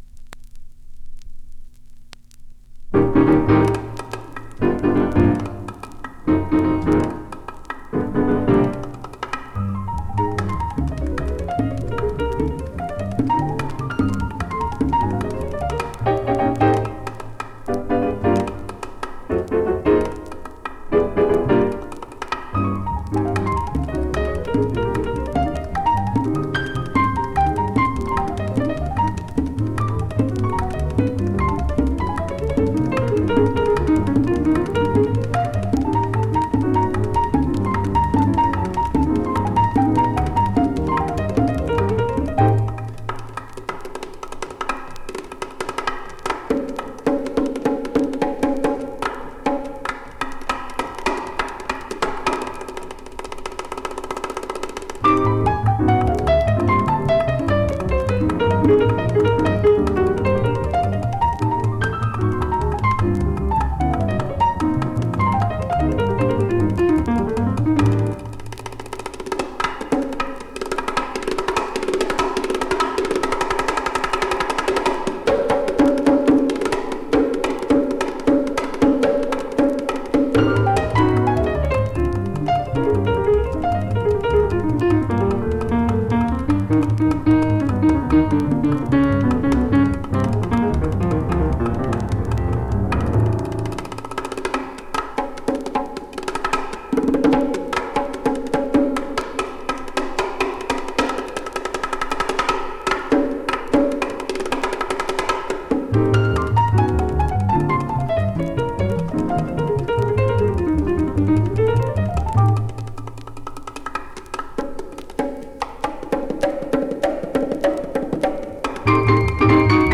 Recorded:  1960 in Paris, France
fun up-tempo workout